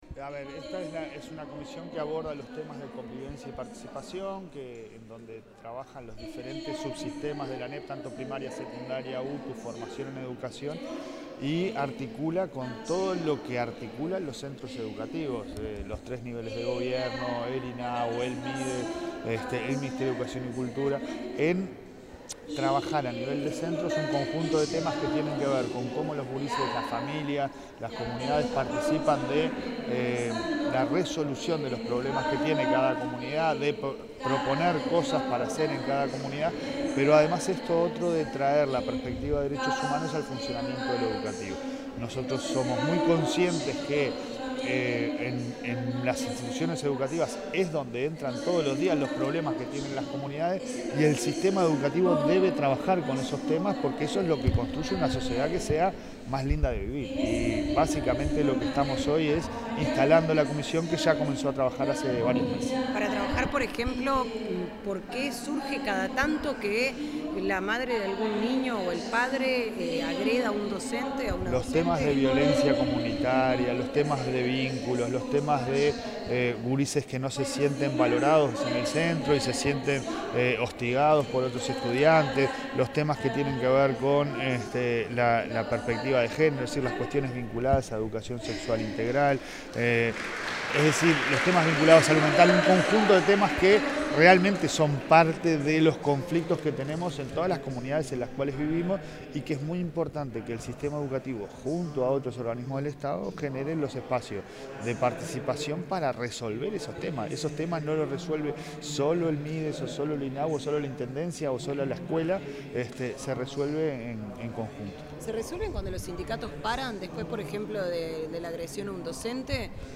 Declaraciones del presidente de ANEP, Pablo Caggiani
Declaraciones del presidente de ANEP, Pablo Caggiani 30/10/2025 Compartir Facebook X Copiar enlace WhatsApp LinkedIn El presidente de la Administración Nacional de Educación Pública (ANEP), Pablo Caggiani, realizó declaraciones en el marco de la presentación de la Comisión de Convivencia y Participación.